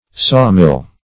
Sawmill \Saw"mill`\, n.